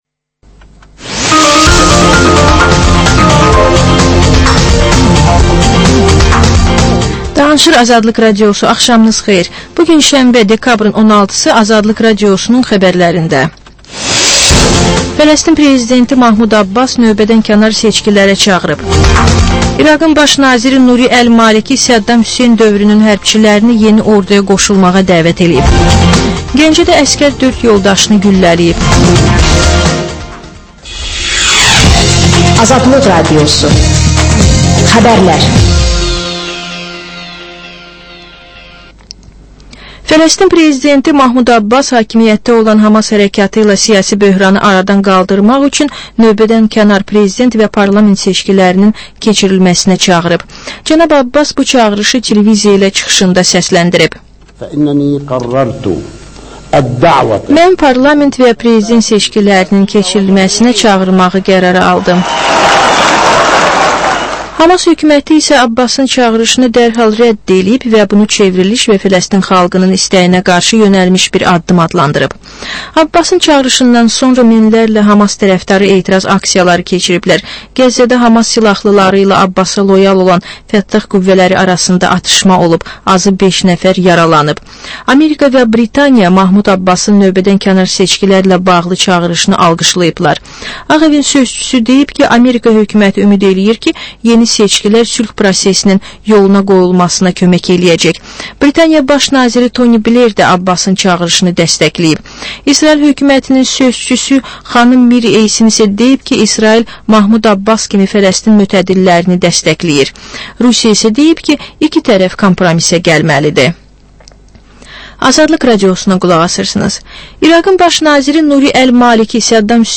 Xəbərlər, reportajlar, müsahibələr